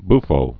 (bfō)